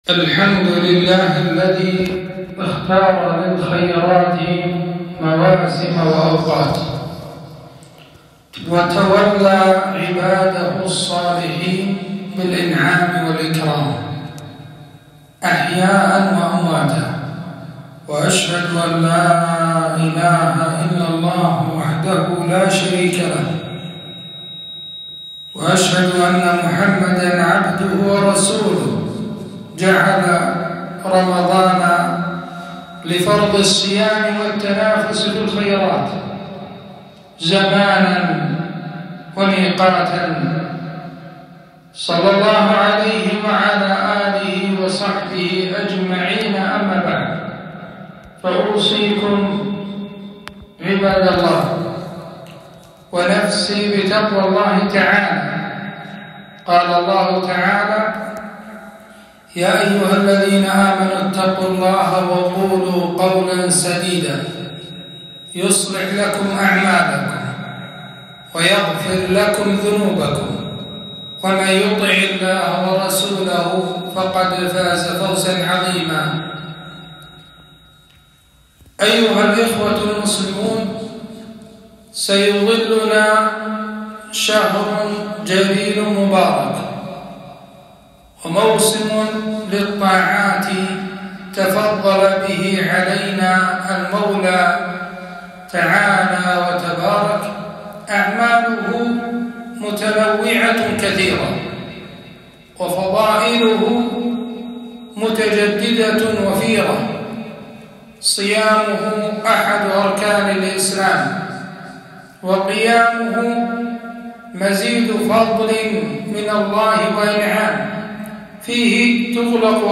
خطبة - رمضان شهر عبادة وعمل